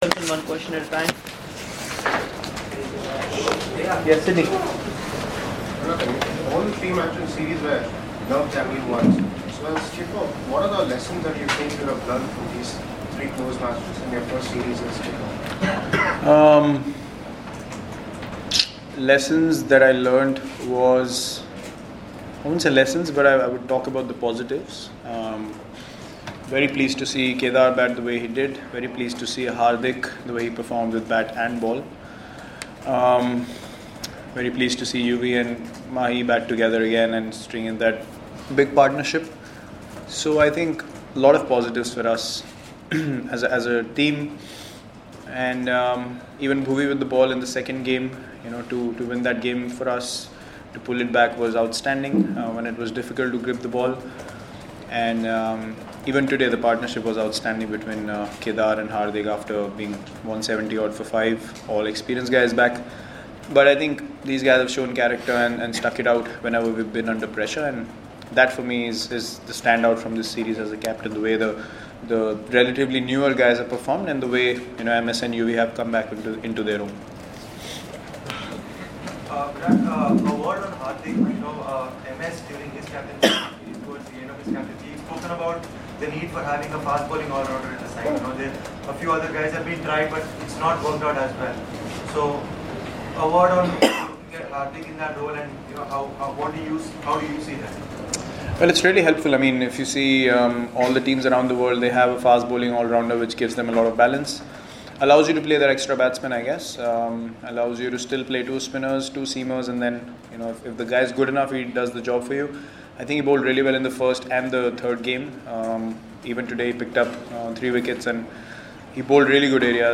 LISTEN: Captain Virat Kohli speaks after 2-1 series win against England.